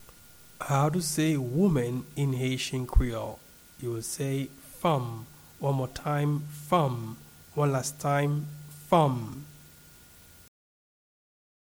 Woman-in-Haitian-Creole-Fanm-pronunciation.mp3